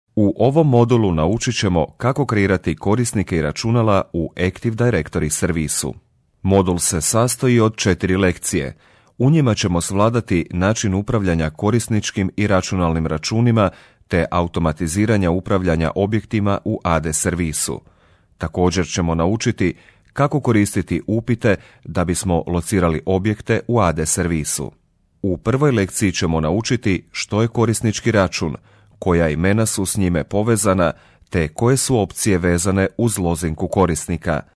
Experienced voice-over talent for Croatian, Bosnian and Serbian market.
Kein Dialekt
Sprechprobe: Sonstiges (Muttersprache):